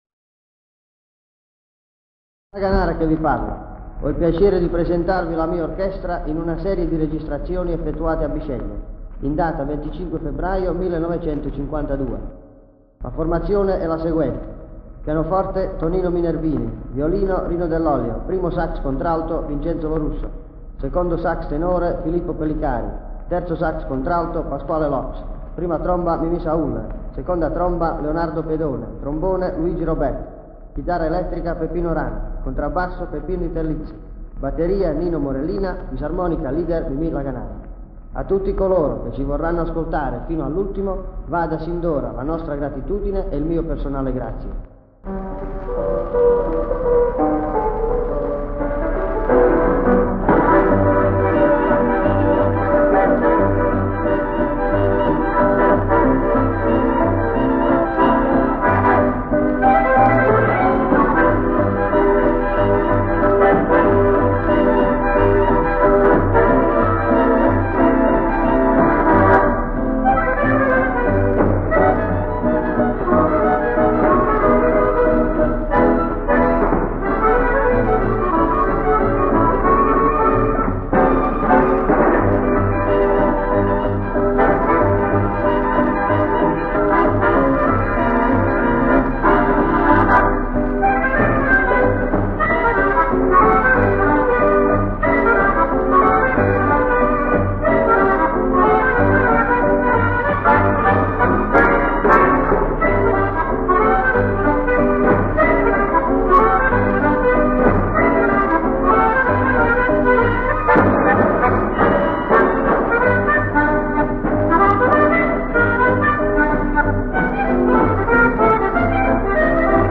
Complesso Musicale Crazy Boys nell'anno 1952 durante un concerto al teatro Politeama Bisceglie.
Prove tecniche di registrazione presso il Politeama Italia di Bisceglie su filo d'acciaio (ditta Radio Valls - Bisceglie)
Presentazione del compresso + Aranciata (Ferrari) violino e fisarmonica; ascolta brano